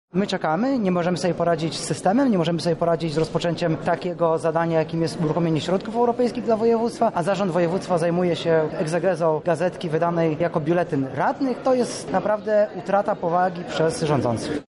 Mogłem poinformować mieszkańców o tym, co jest dla mnie ważne- mówi Artur Soboń, przewodniczący klubu radnych PiS.